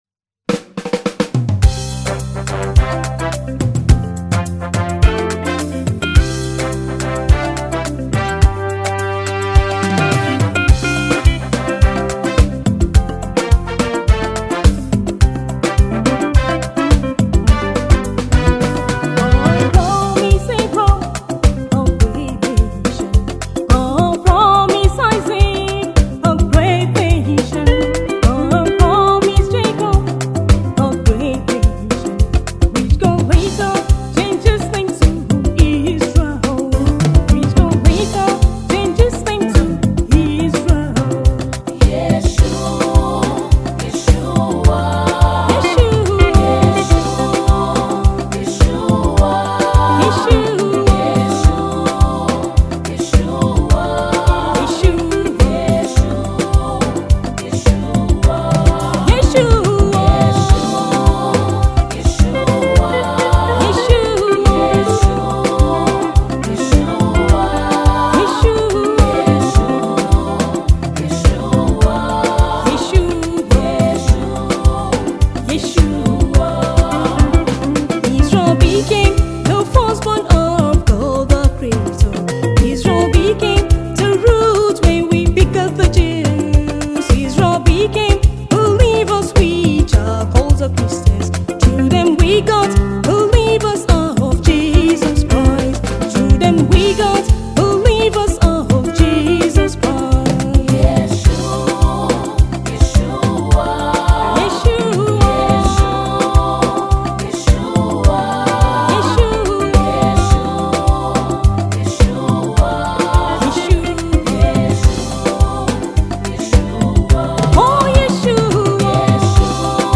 praise song